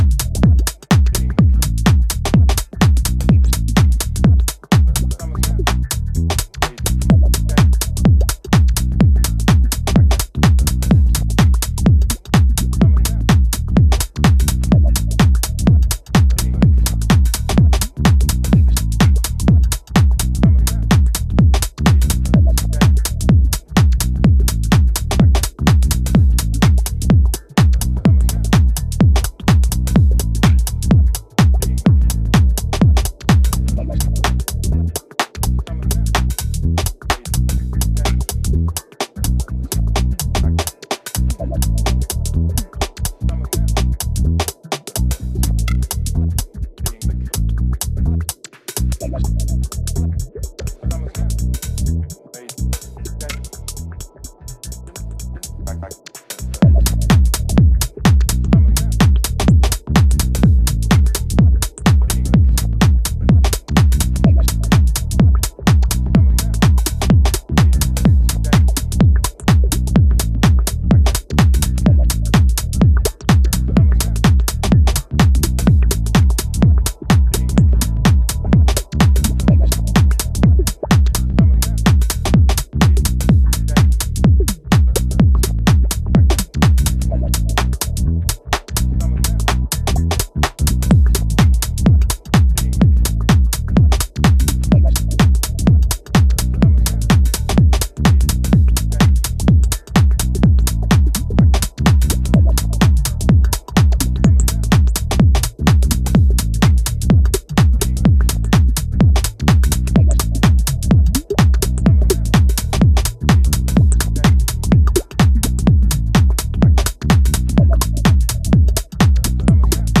stomping and dreamy sounds
This is an absolute weapon for dancefloors!